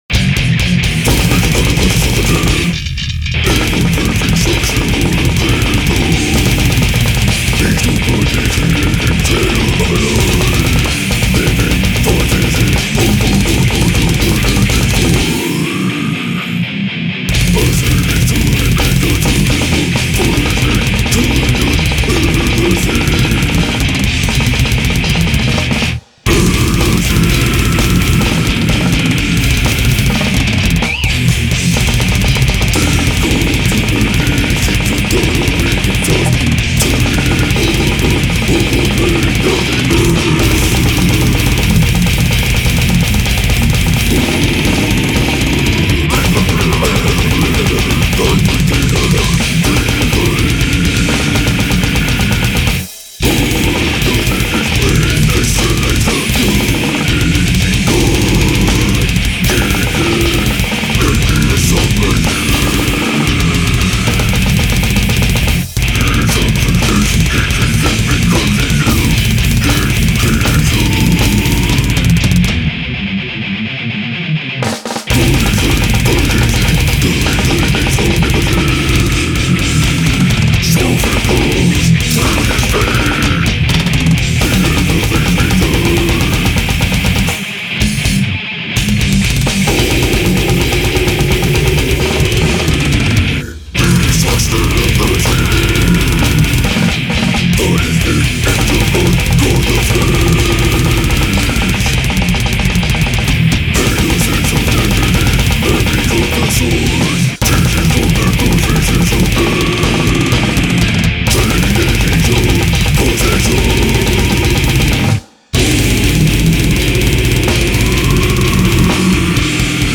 Ну играю брутал дэт
у меня кот похоже делает когда начинает мяукать, если его потрясти
ну я такое не понимаю в целом, могу только грувчик заценить когда там помедленнее бек